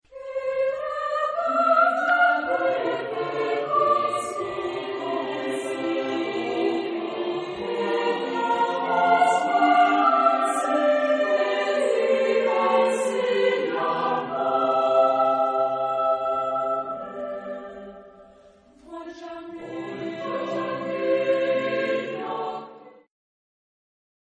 Epoque: 20th century  (1900-1949)
Genre-Style-Form: Madrigal ; Secular
Type of Choir: SATB  (4 mixed voices )
Tonality: D minor